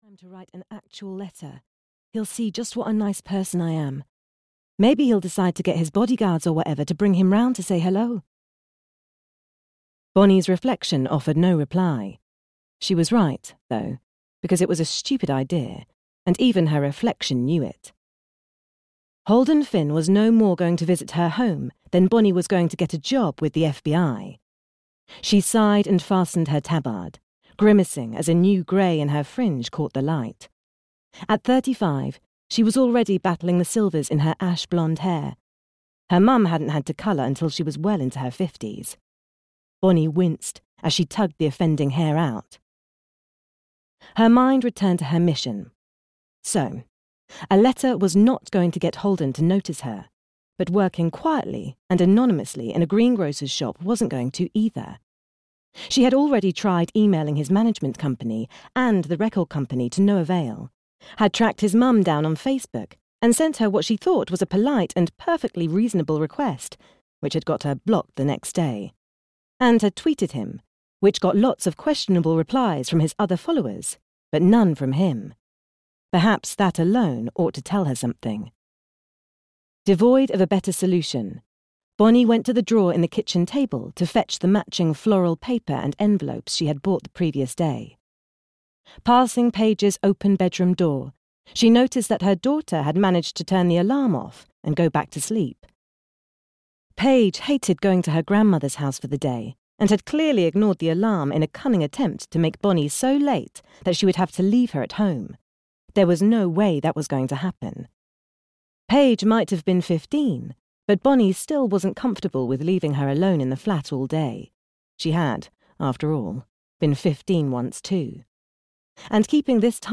Audio knihaThe Time of My Life (EN)
Ukázka z knihy